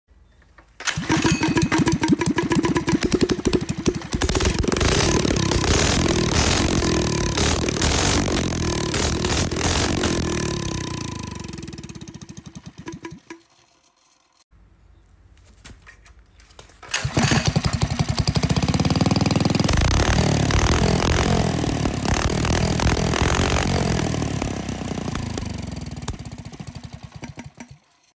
With sounds better old engine